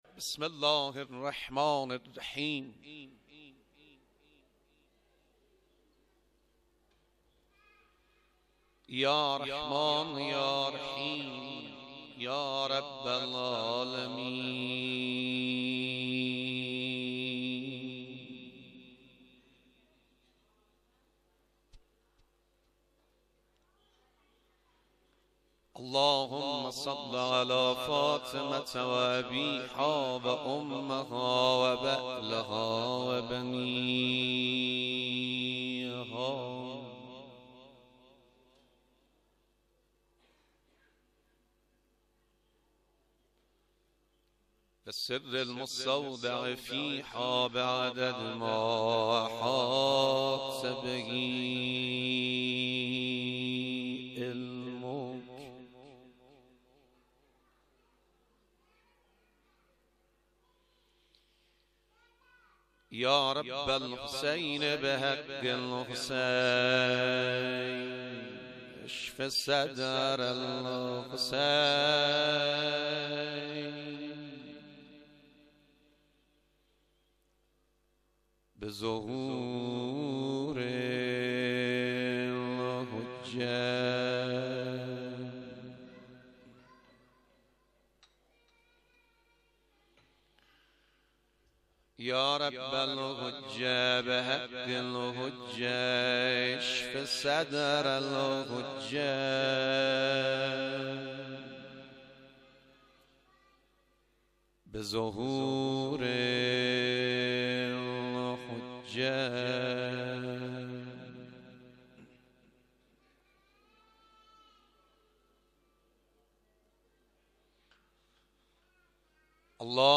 کلیپهای صوتی شب چهارم محرم سال 96 حسینیه پنجاهه نائین
شب-چهارم_-مصیبت.mp3